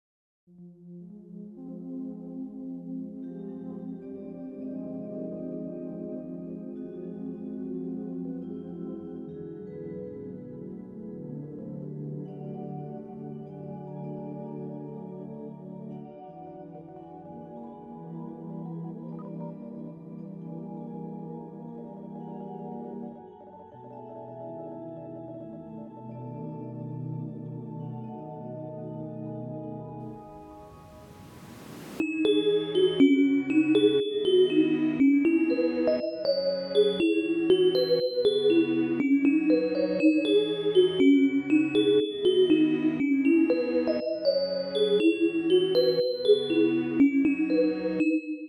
Sure is fun trying new things, (sure do wonder why synth1 always gets angry)